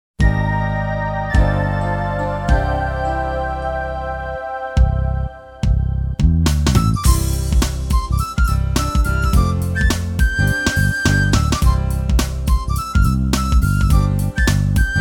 Kids